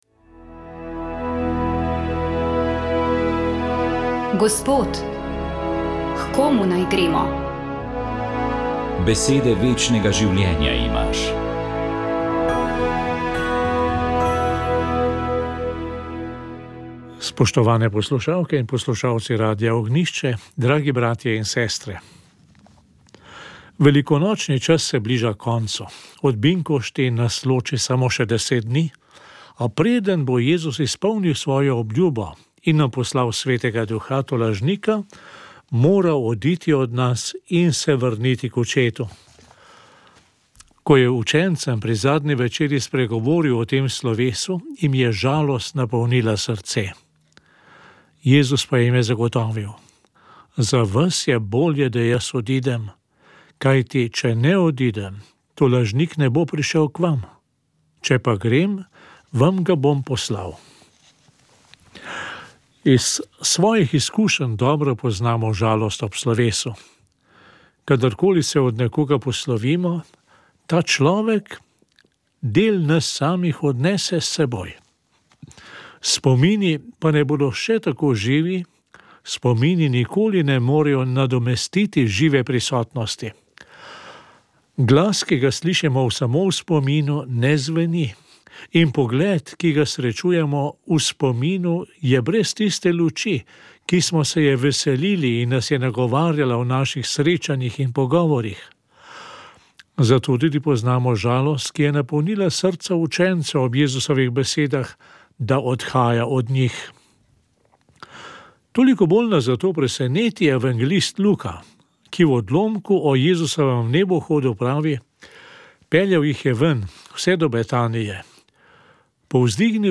duhovnost nagovor papež misijoni služenje